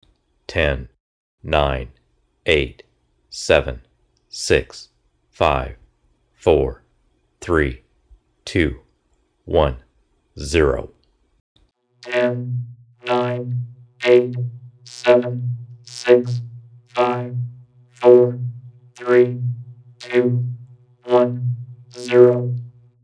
Sounds and voices
Vocoding voice with 120Hz sawtooth is Cylon-ish …